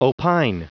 Prononciation du mot opine en anglais (fichier audio)
Prononciation du mot : opine